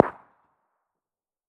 CookoffSounds / shotbullet / far_1.wav
Cookoff - Improve ammo detonation sounds